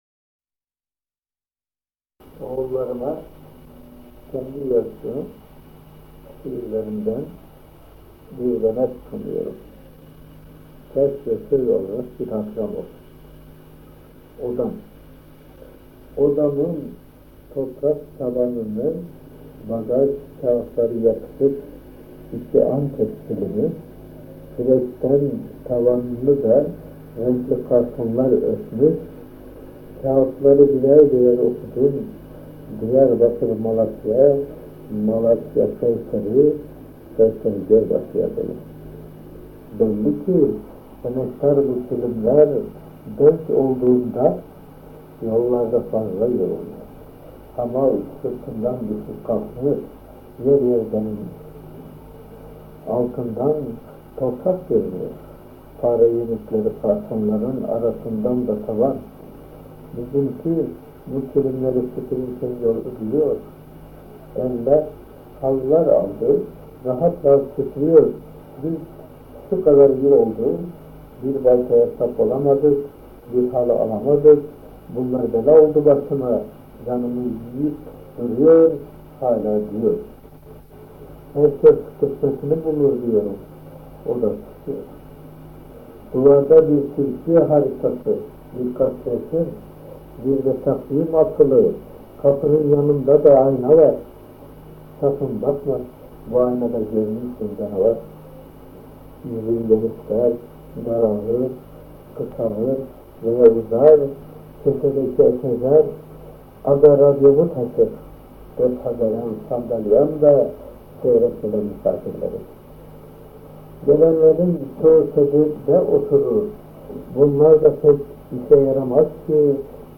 KENDİ SESİNDEN ŞİİRLER